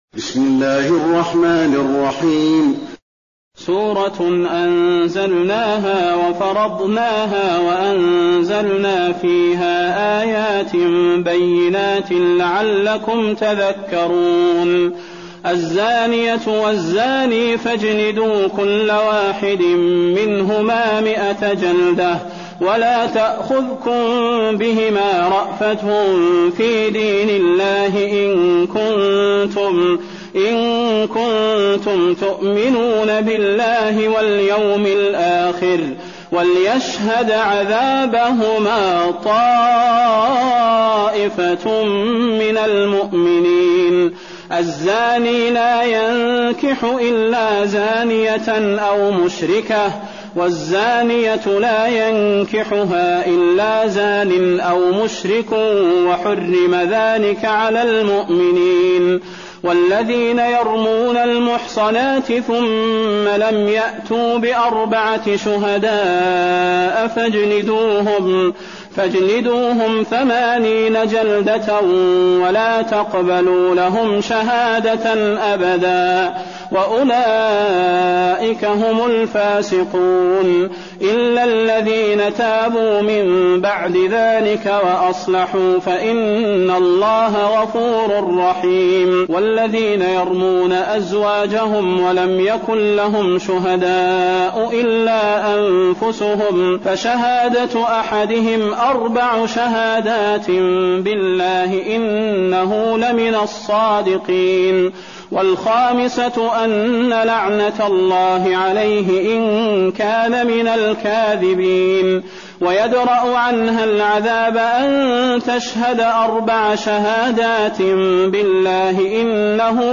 المكان: المسجد النبوي النور